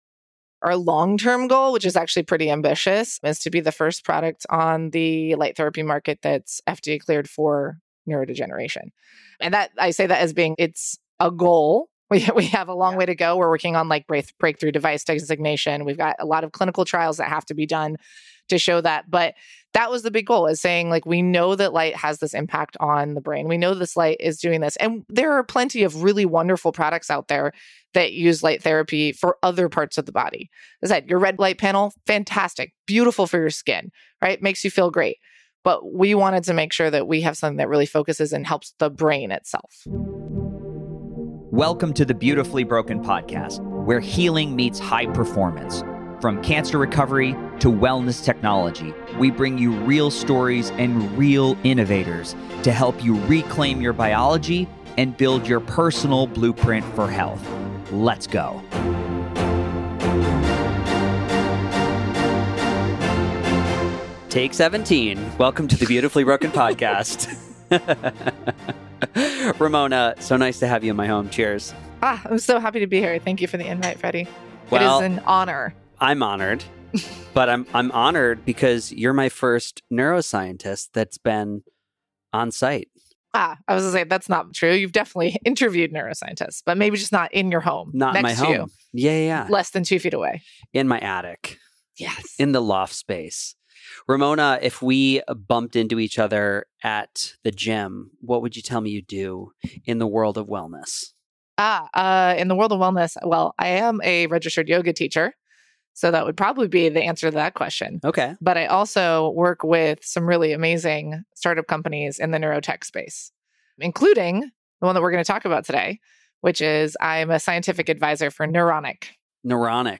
A Neuroscientist Explains Neuronic